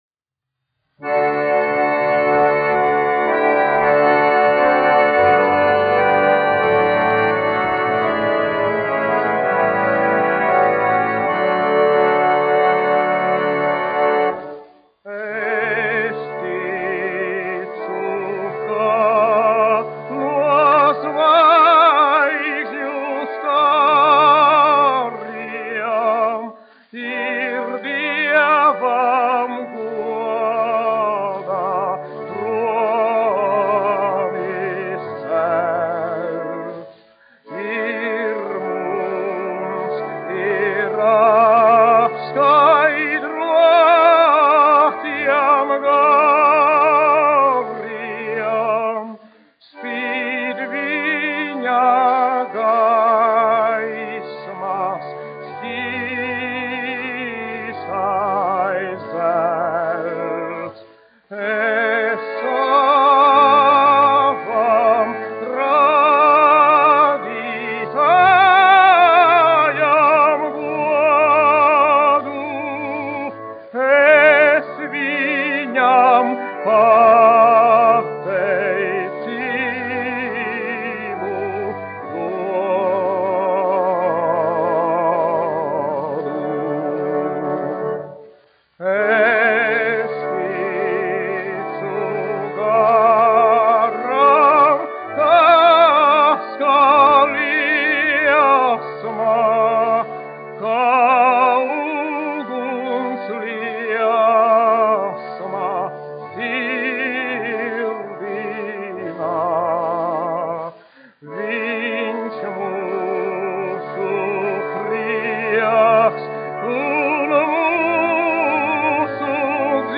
1 skpl. : analogs, 78 apgr/min, mono ; 25 cm
Korāļi
Garīgās dziesmas ar ērģelēm
Skaņuplate